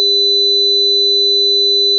Highpass Digital Filter Example
A highpass Chebyshev Type 1 filter is designed using the method illustrated in Example 12.5. The cutoff frequency is 1000 hz and the sampling period is T=1/10000.
The red circles show the values of |H| at the digital frequencies corresponding the analog frequencies of 400 hz and 4000 hz, which are the components in x(t).
Click on the time responses to hear the resulting signal.